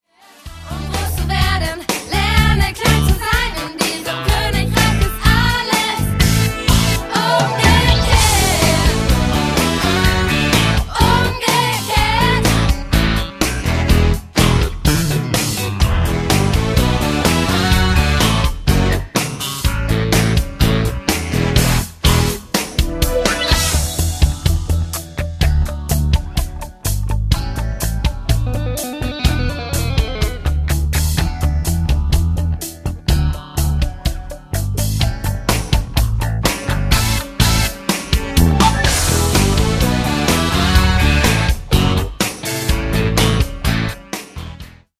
Die spritzigen und eingängigen Songs